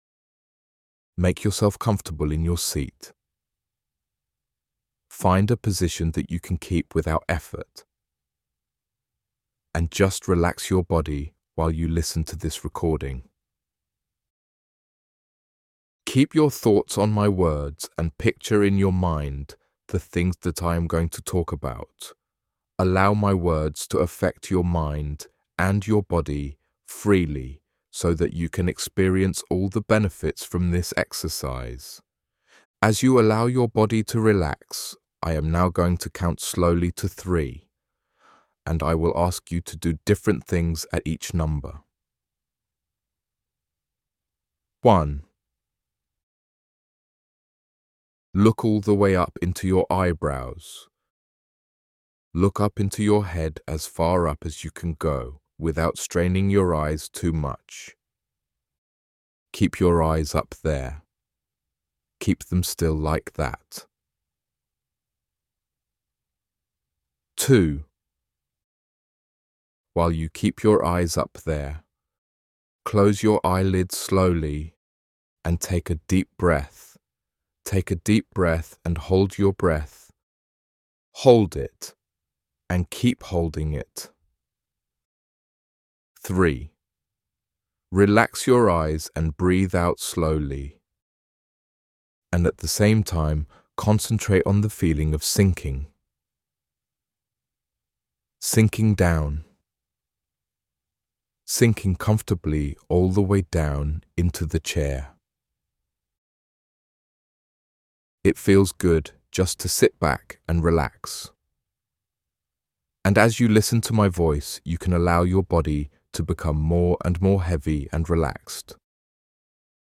Gut-directed hypnotherapy home exercise – Bradford Teaching Hospitals NHS Foundation Trust
Gut-Directed-Hypnotherapy-Home-Exercise.mp3